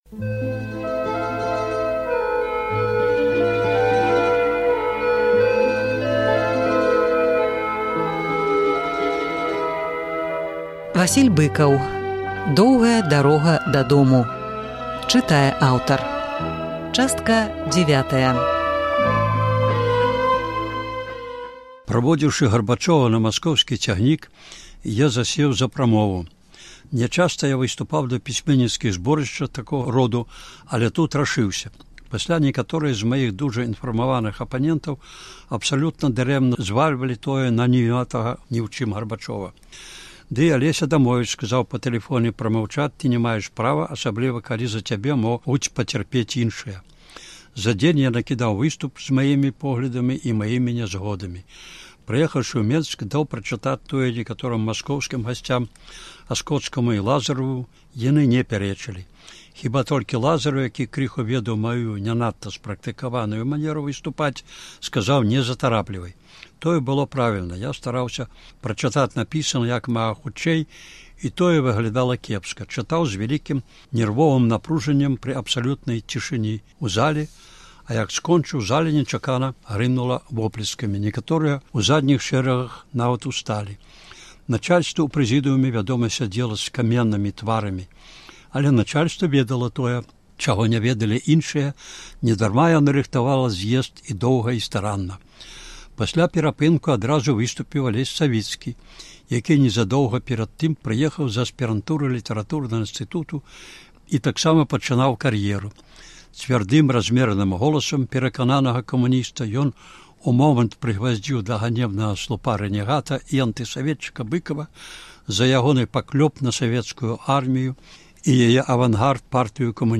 Успаміны Васіля Быкава «Доўгая дарога дадому». Чытае аўтар.